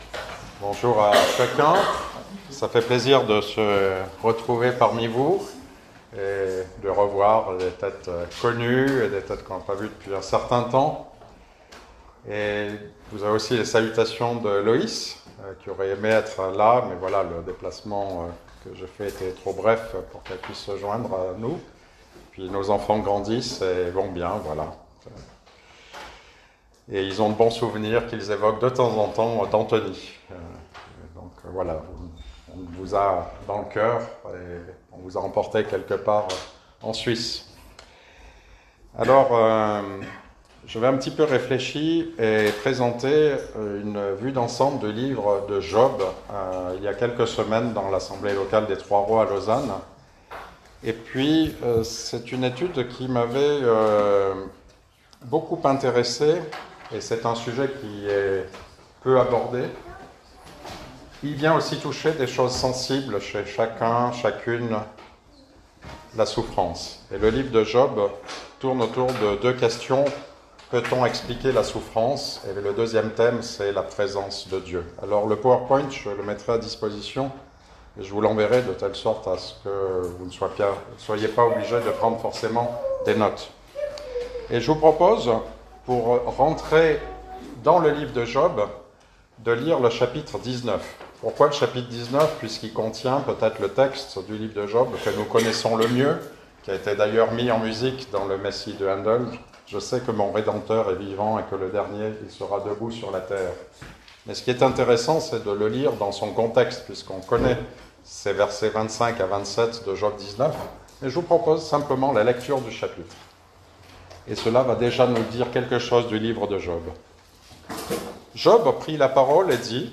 Job Voir le slide de la prédication https